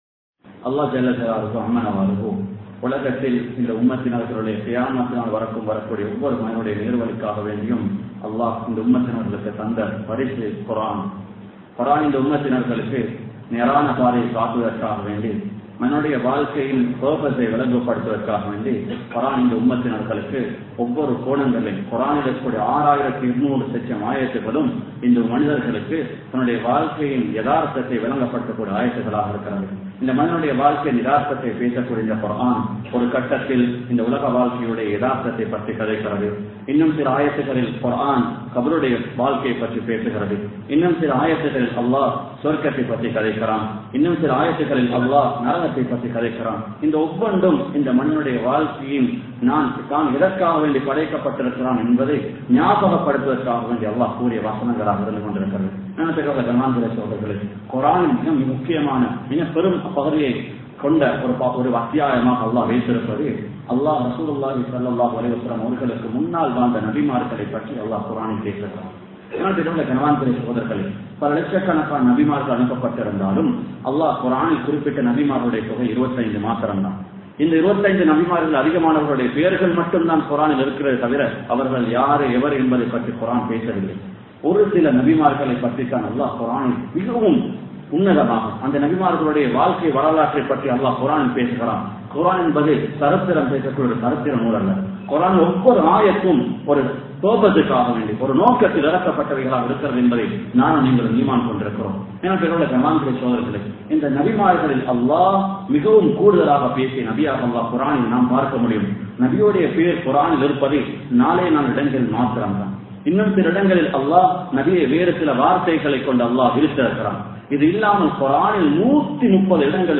Karoonin Varalaaru Koorum Padippinai (காறூனின் வரலாறு கூறும் படிப்பினை) | Audio Bayans | All Ceylon Muslim Youth Community | Addalaichenai
Colombo 11, Samman Kottu Jumua Masjith (Red Masjith)